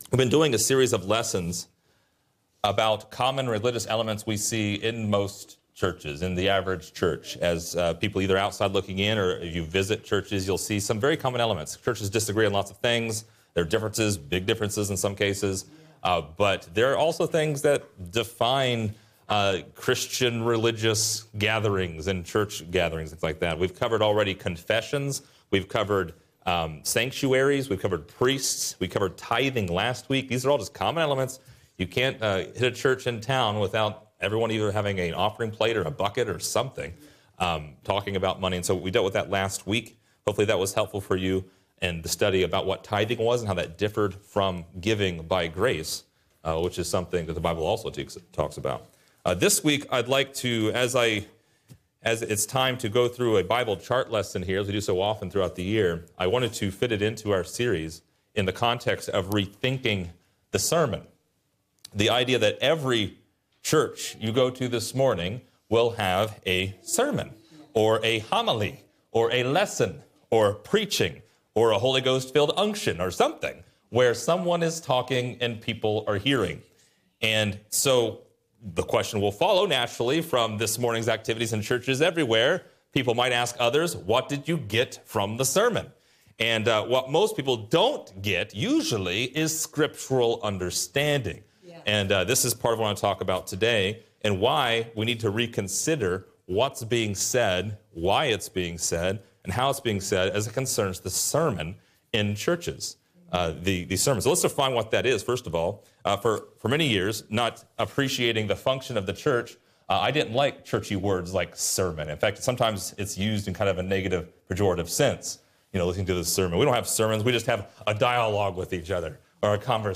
But what is the right way to preach a sermon? Find out in this lesson!